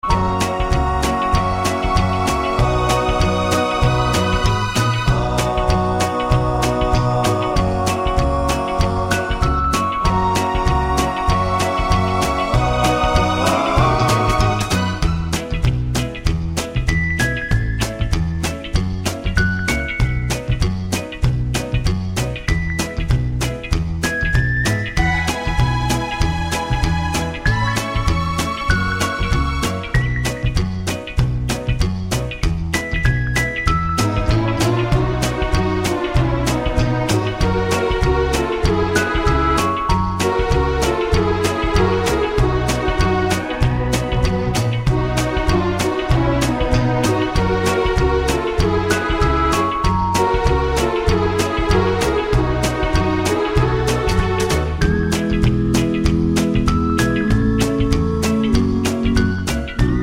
no Backing Vocals Country (Female) 2:20 Buy £1.50